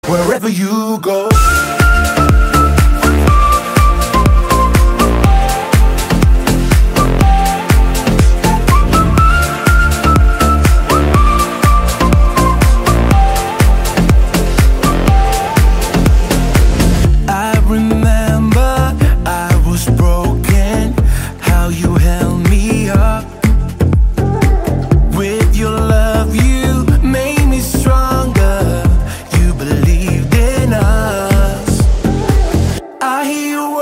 Kategori POP